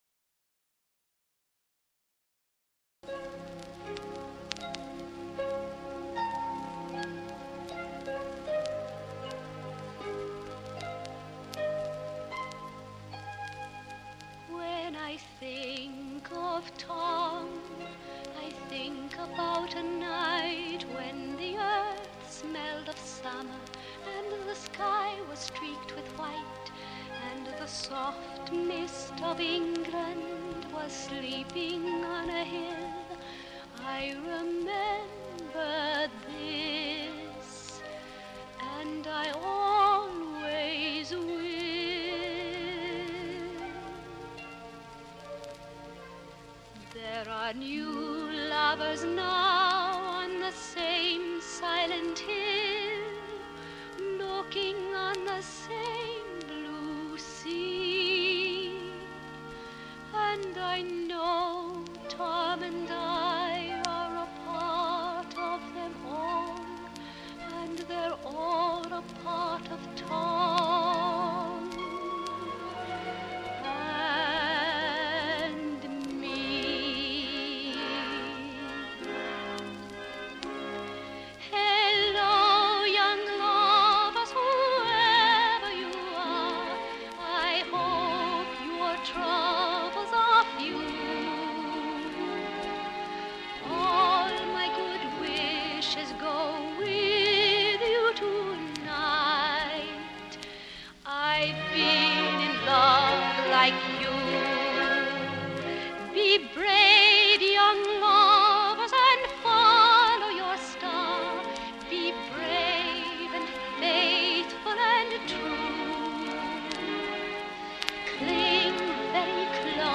HIGH FIDELITY STEREOPHONIC RECORDING